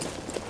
Gear Rustle Redone
tac_gear_1.ogg